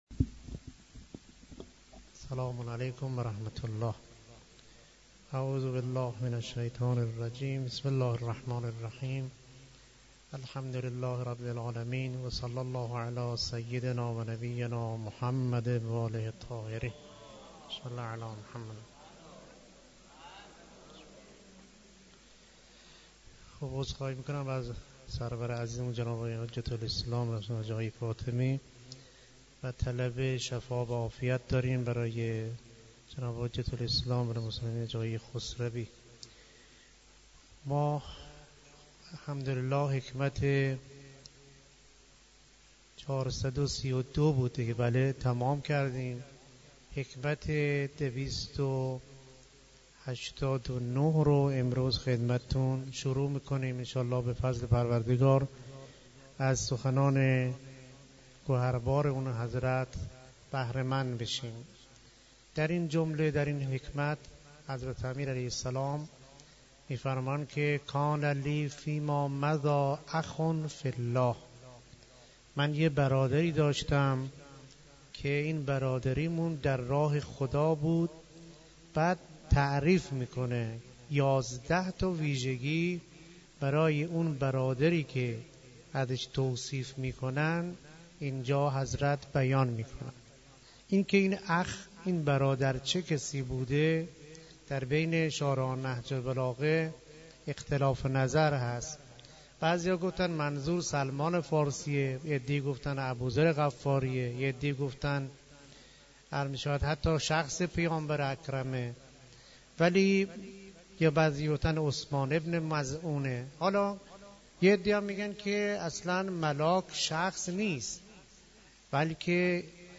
سخنرانی استاد محترم گروه معارف اسلامی دانشگاه
در مسجد دانشگاه کاشان